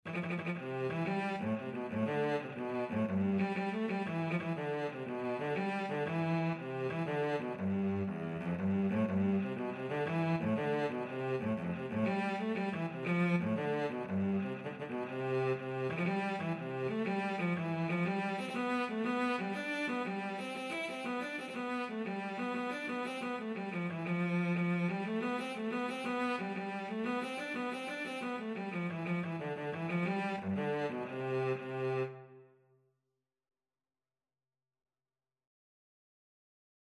Free Sheet music for Cello
6/8 (View more 6/8 Music)
E3-E5
C major (Sounding Pitch) (View more C major Music for Cello )
.=120 Graciously
Instrument:
Traditional (View more Traditional Cello Music)